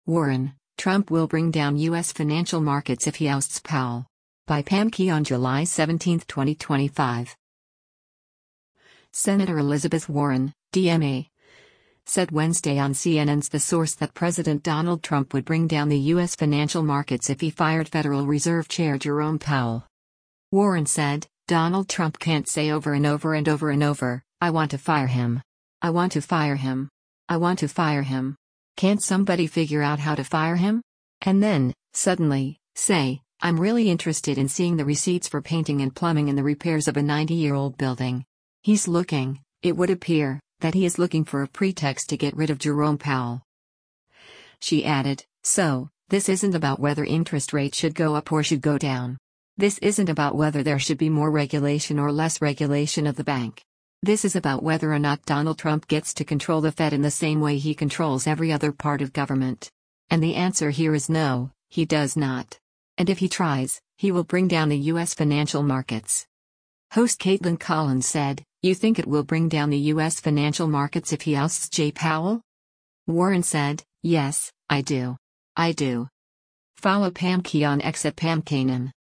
Senator Elizabeth Warren (D-MA) said Wednesday on CNN’s “The Source” that President Donald Trump would “bring down the U.S. financial markets” if he fired Federal Reserve Chair Jerome Powell.
Host Kaitlan Collins said, “You think it will bring down the U.S. financial markets if he ousts Jay Powell?”